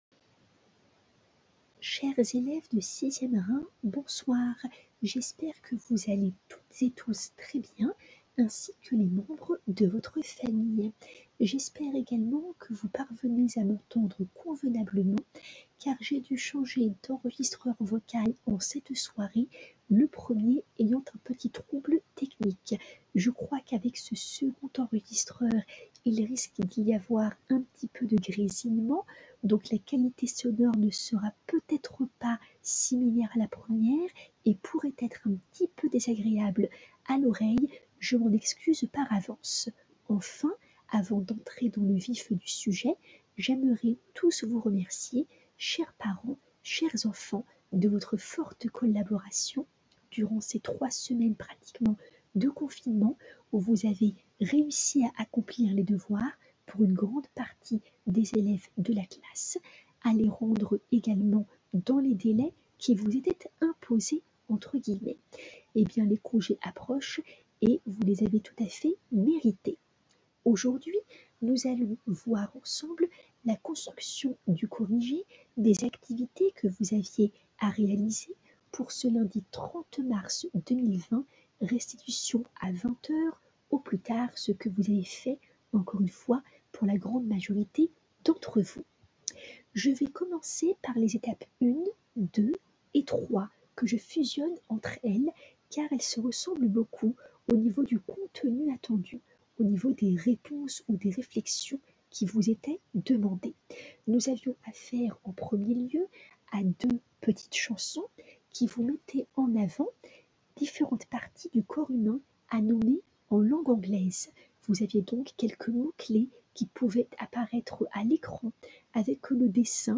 P.S. 2: veuillez m'excuser pour les petits grésillements des pistes audio proposées, s'il vous plaît: ayant rencontré un petit trouble technique avec l'enregistreur MP3 habituel, il m'a fallu disposer d'une nouvelle ressource.
Audio 1 du professeur, d'une durée de 05:05: